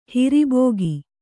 ♪ hiri bōgi